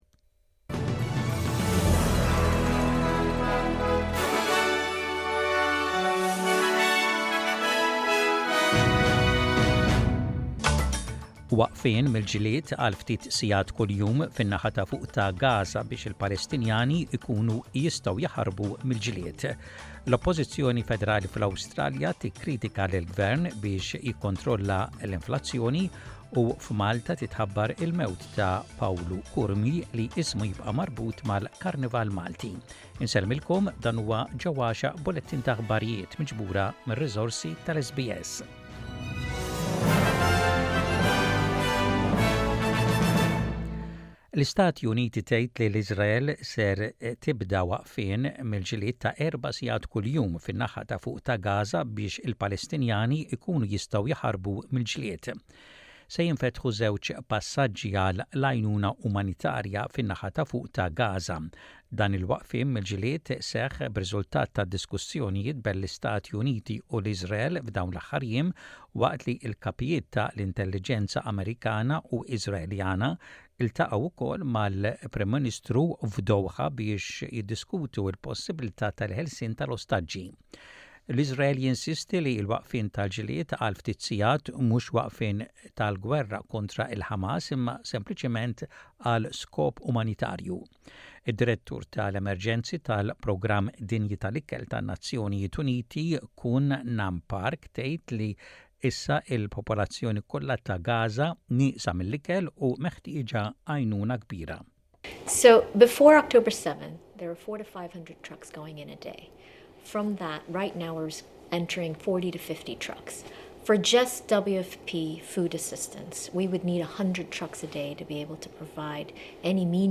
SBS Radio | Maltese News: 10.11.23